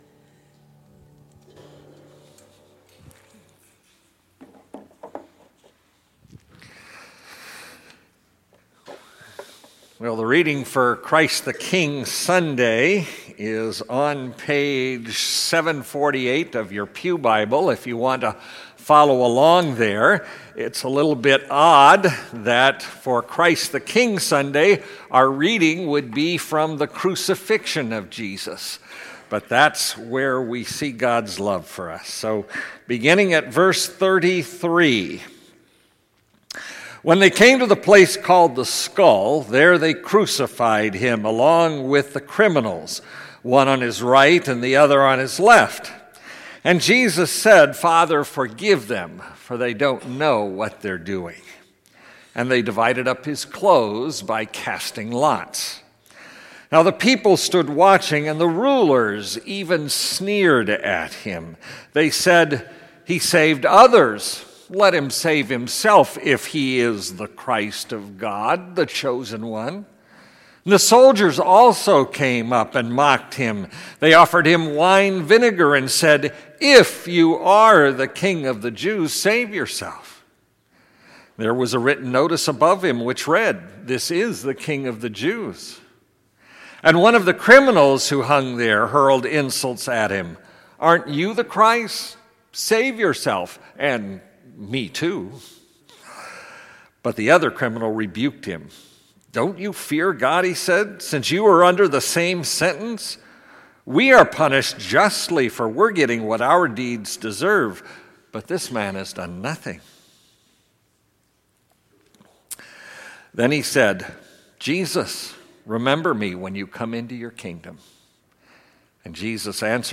Our Redeemer Lutheran Church Garden Grove Sermons